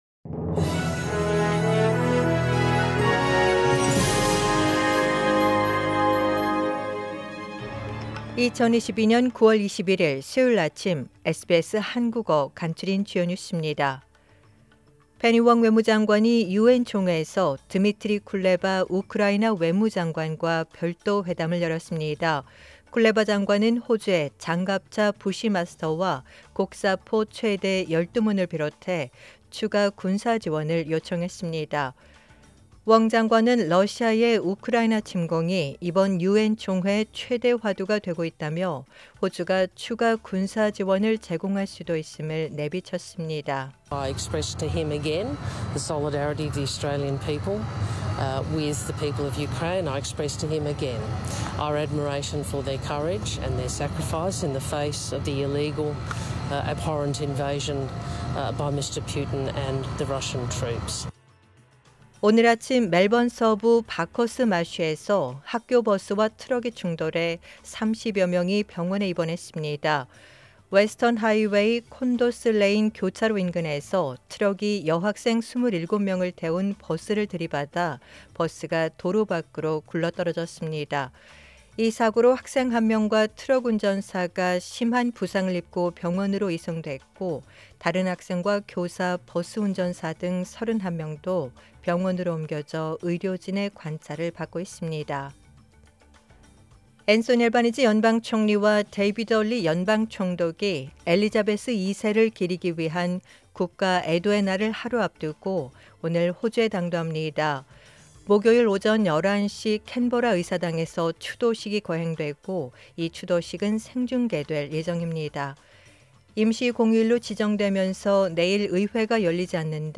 SBS 한국어 아침 뉴스: 2022년 9월 21일 수요일